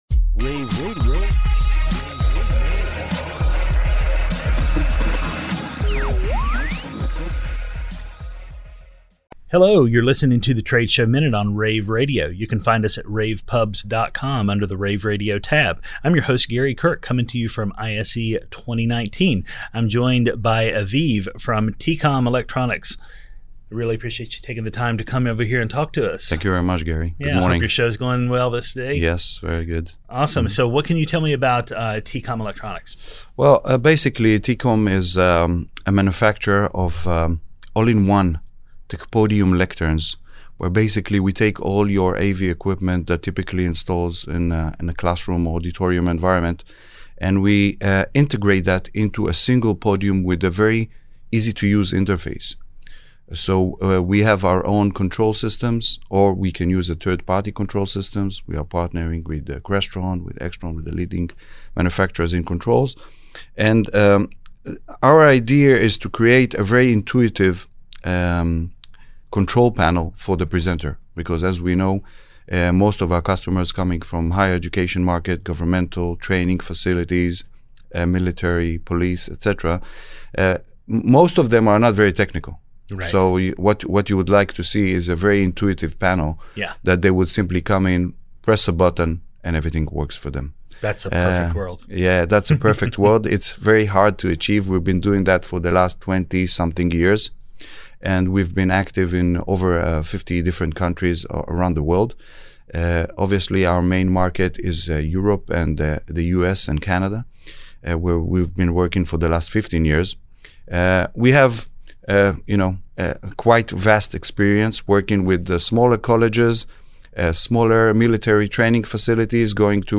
February 6, 2019 - ISE, ISE Radio, Radio, rAVe [PUBS], The Trade Show Minute,